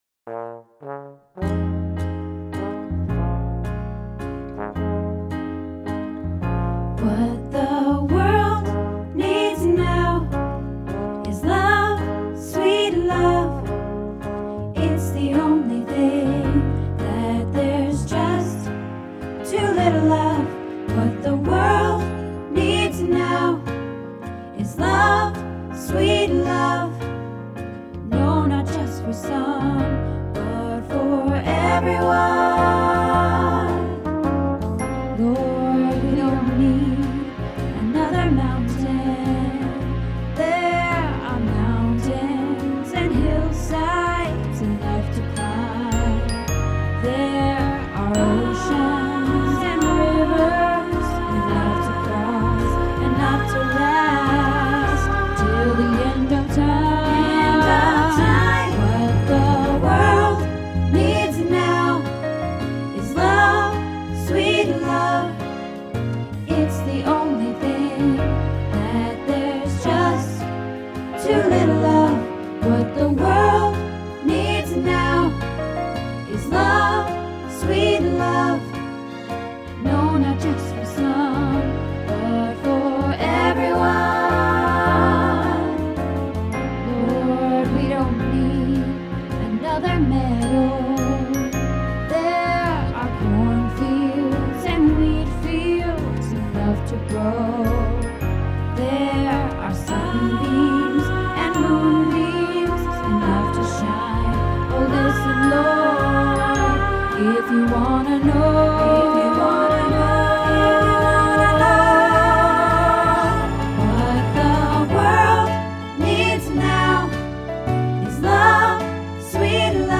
What the World Needs Now – Practice | Happy Harmony Choir